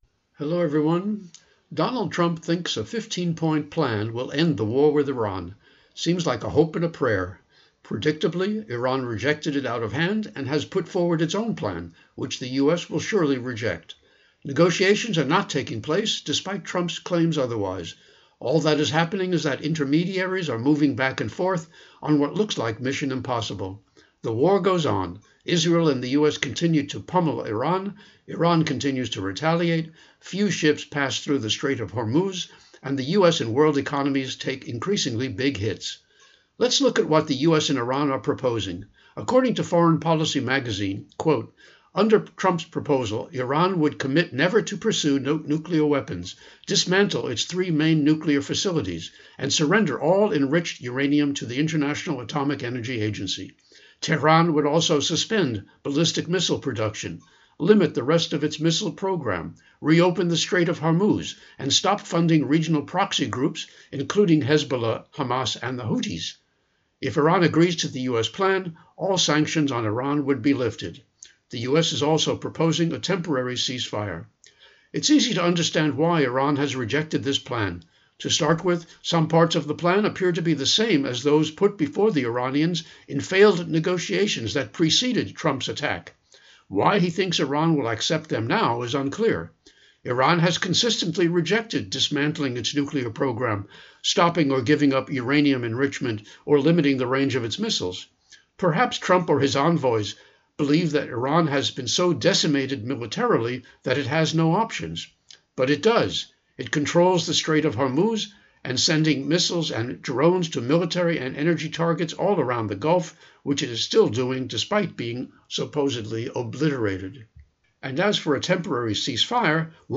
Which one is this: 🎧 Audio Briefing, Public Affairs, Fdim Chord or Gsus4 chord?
Public Affairs